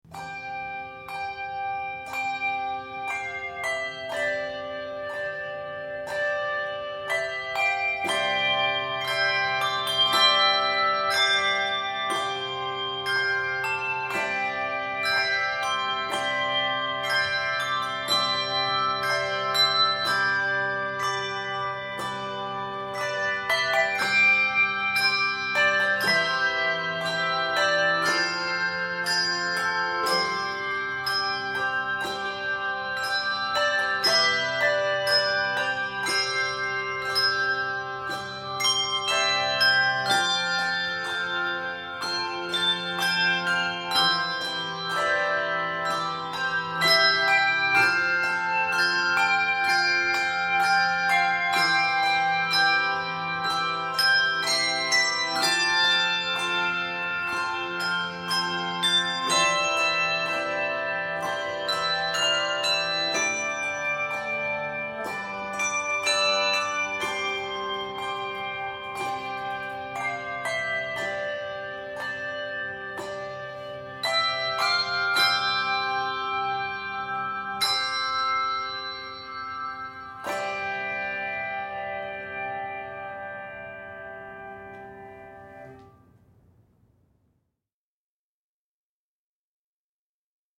for 3-5 octaves